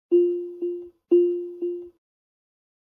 seatbeltWarn.wav